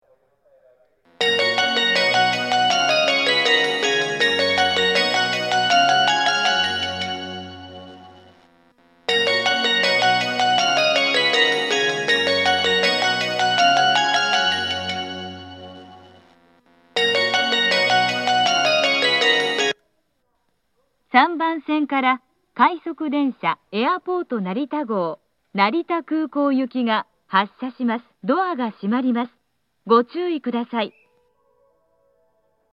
発車メロディー 曲は短いのですが、停車時間の短い列車は余韻切りが多いです。
2,3番線ホームの千葉寄りに1台だけ小丸VOSSスピーカーが設置されています。